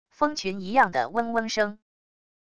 蜂群一样的嗡嗡声wav音频